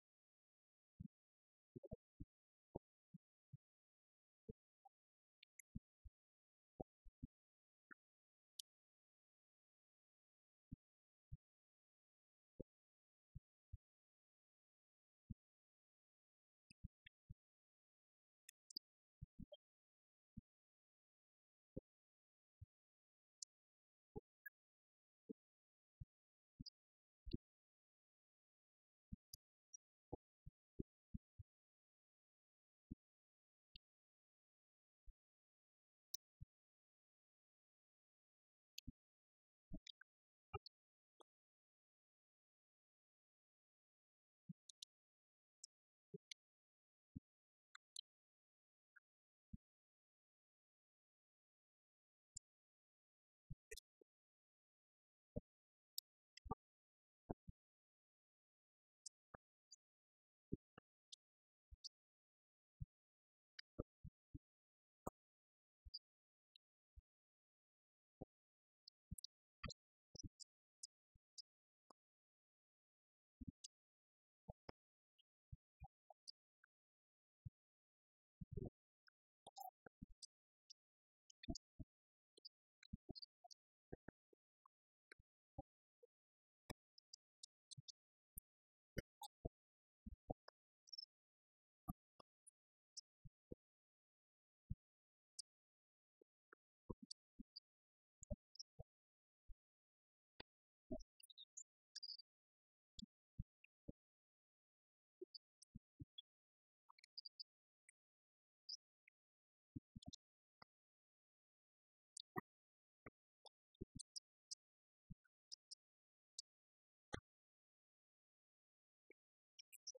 cop-news-conf-with-mayor-9-25-15.mp3